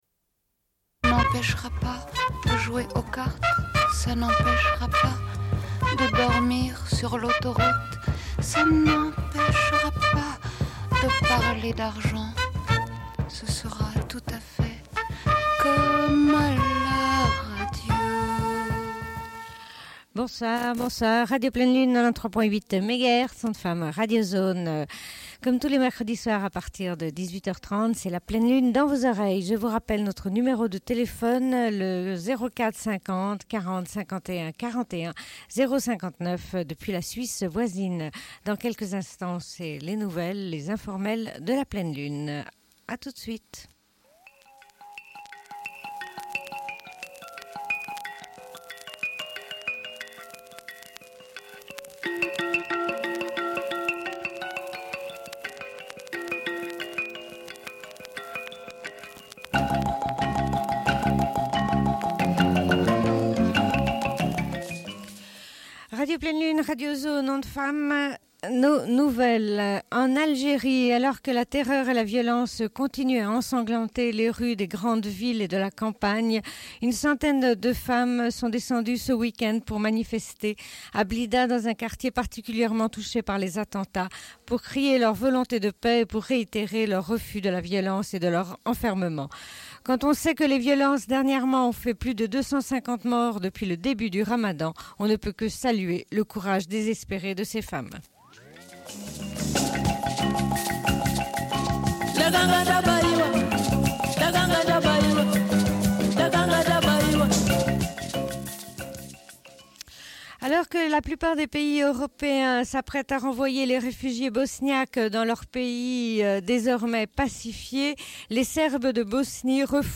Bulletin d'information de Radio Pleine Lune du 21.06.1995 - Archives contestataires
Une cassette audio, face B